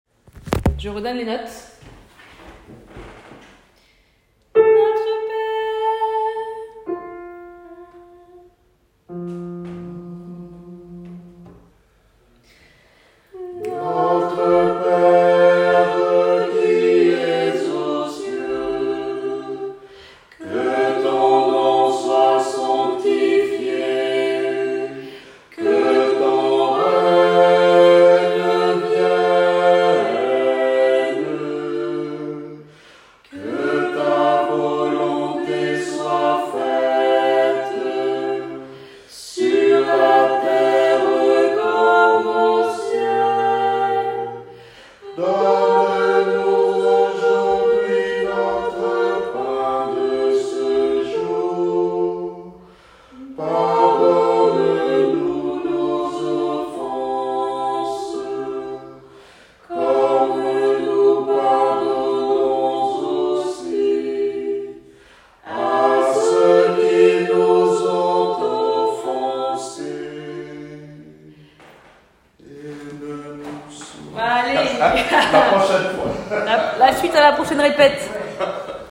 Quelques extraits sonores de la chorale
La chorale en train de répéter dans la bibliothéque du temple.